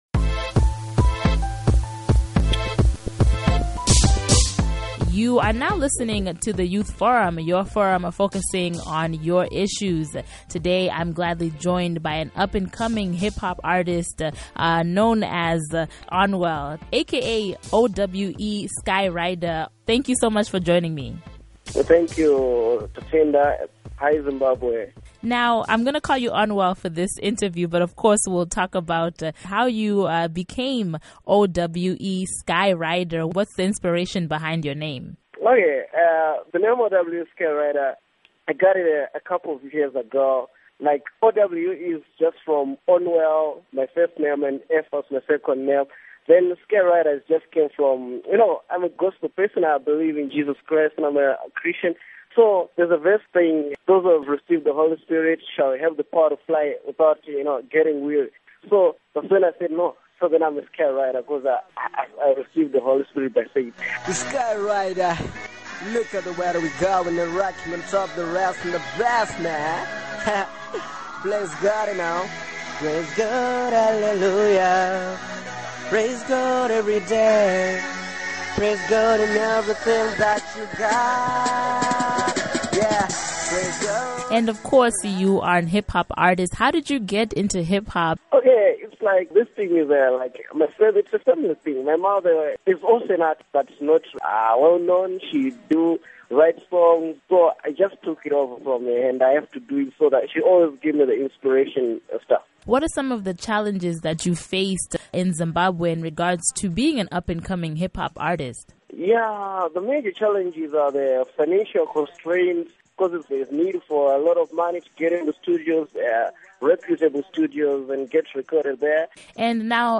Youth Forum Interview